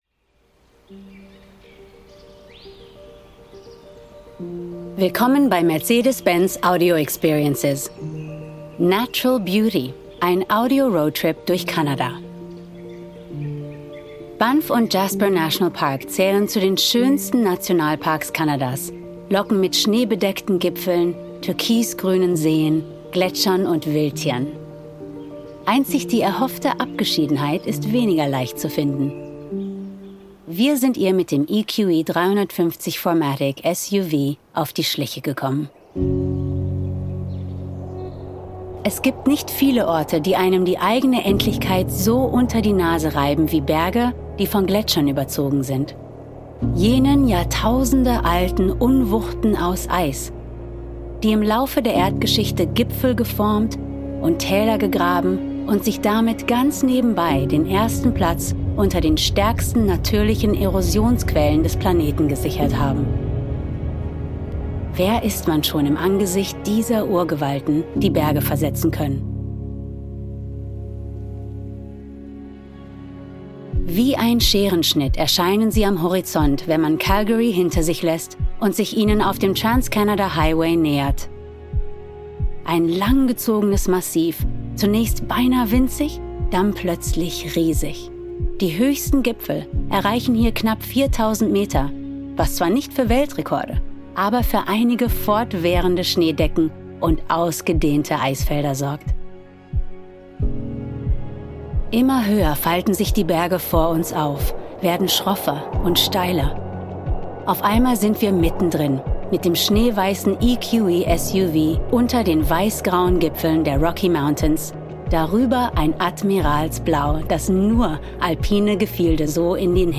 Weve turned some of our favourite road trips into audio adventures that will transport you to destinations far away.